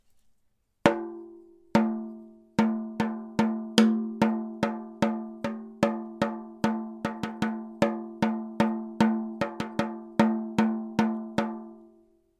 Eine kleine Trommel aus Holz, bunt bemalt und mit Naturfell bespannt. Das Naturfell hat einen warmen Klang und wird auch bei heftigen Schlägen nicht aufdringlich.
Der Klang ist nicht blechern wie bei anderen Kindertrommeln, sondern angenehm und natürlich.
Klangbeispiel Kindertrommel
kindertrommeln-klein.mp3